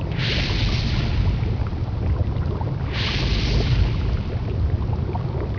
Index of /pub/quakerepo/fortress/sound/ambience